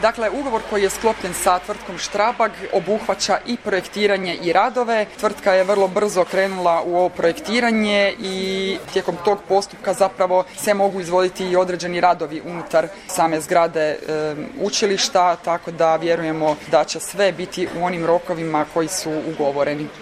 Više o tome, gradonačelnica Petrinje Magdalena Komes